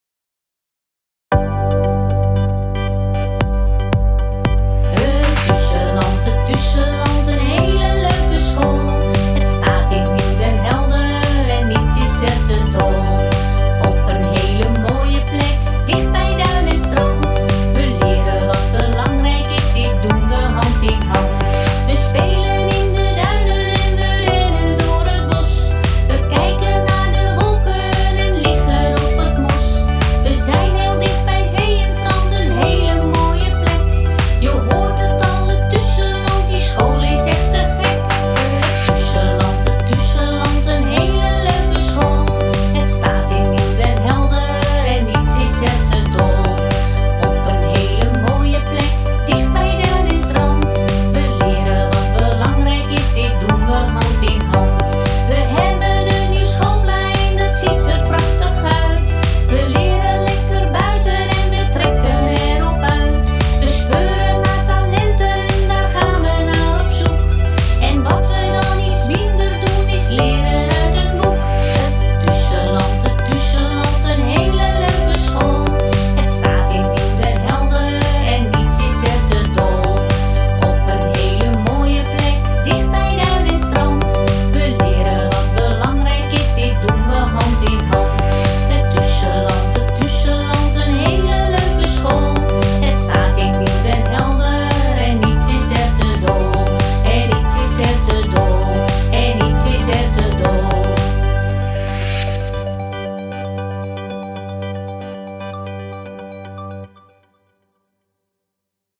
t_tusalant_met_vocale_begeleiding_master1.mp3